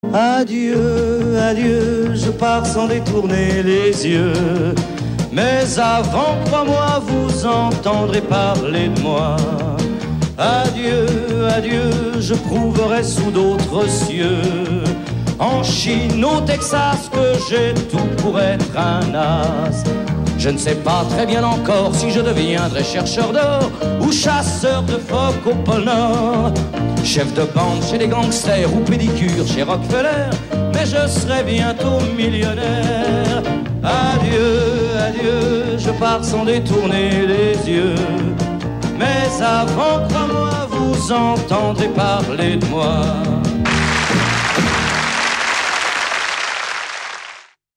INEDITS SOLO TV/RADIO